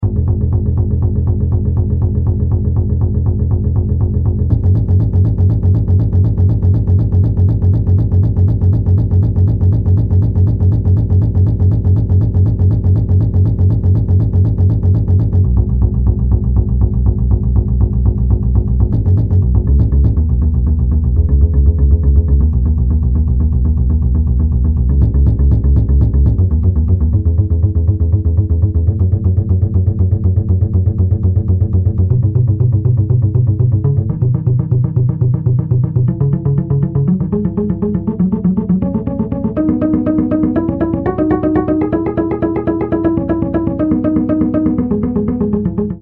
Face a este panorama pouco promissor, optou-se pelos parâmetros abaixo para sonificação, os quais buscam refletir o crescimento gradual e robusto da democracia entre 1840 e 2010 e a intensidade da regressão democrática após 2011.
Instrument: Double Bass Key: G Major Scale Range: 3 Octaves
Master Tempo (BPM): 240